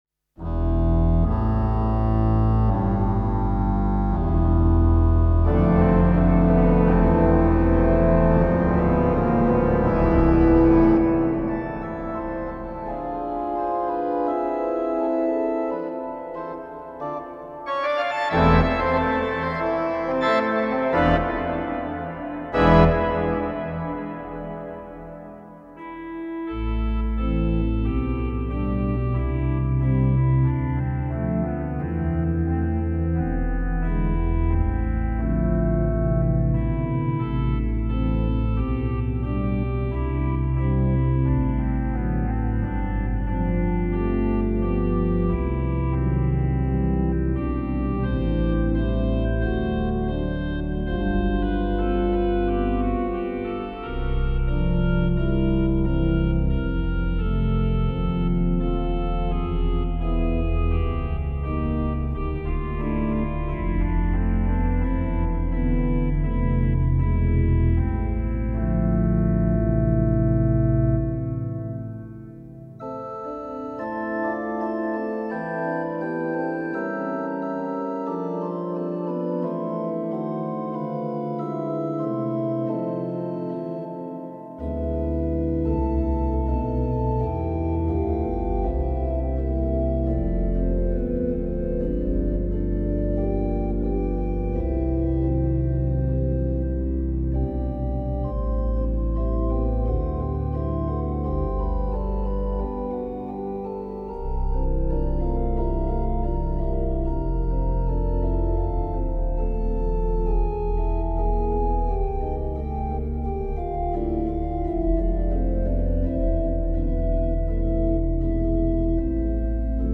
Une magnifique mélodie (typiquement anglaise), qui pourrait tout autant sortir d'un “Hymn-book” (recueil de cantiques). Très chantante.
Le morceau a trois parties avec une forte registration : l'introduction, la variation 2 et le final.
L'introduction avec une tonalité sombre, la variation 2 sans 16 pied et avec un peu plus de clarté. Et la pleine puissance seulement dans le final.
La variation 1 exige des flûtes, tandis que la variation 3 est au contraire bien mise en valeur avec des cordes.